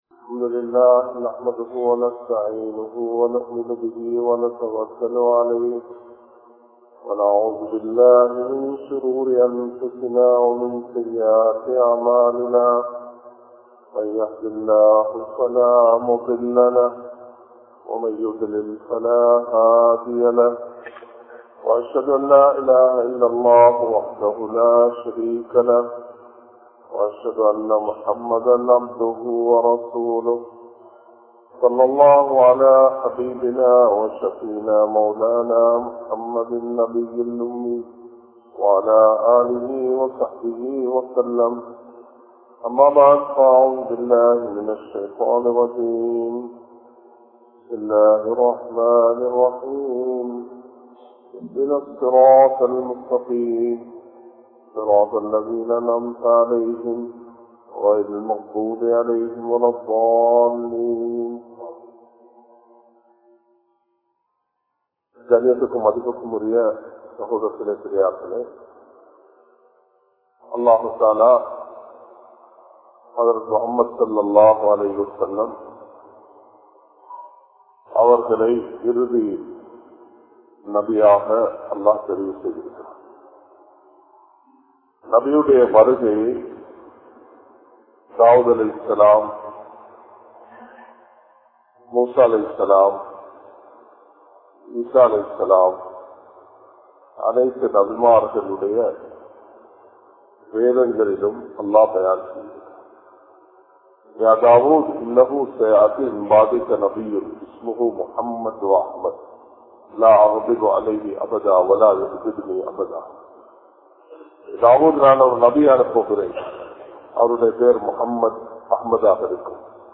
Dhawaththin Avasiyam (தஃவத்தின் அவசியம்) | Audio Bayans | All Ceylon Muslim Youth Community | Addalaichenai
Colombo, GrandPass Markaz